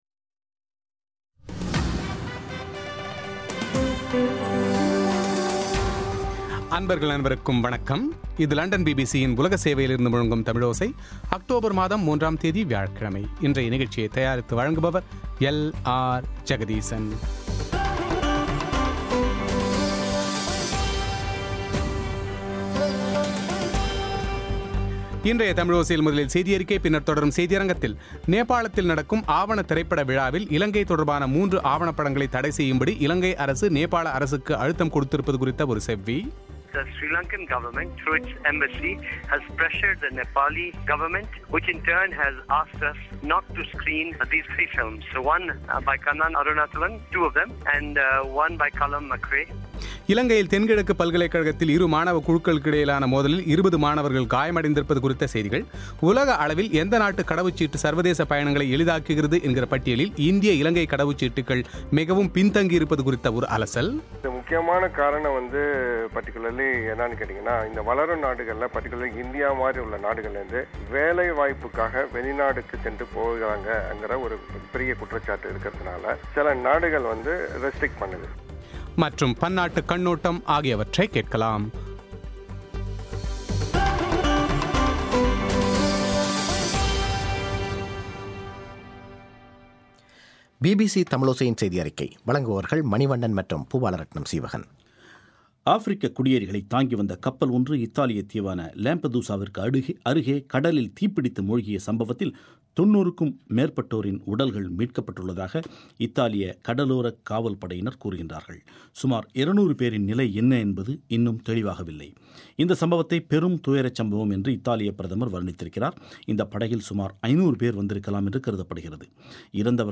நேபாளத்தில் நடக்கும் ஆவணப்படத் திரைப்படவிழாவில் இலங்கை தொடர்பான மூன்று ஆவணப்படங்களை தடைசெய்யும்படி இலங்கை அரசு நேபாள அரசுக்கு அழுத்தம் கொடுத்திருப்பது குறித்த செவ்வி;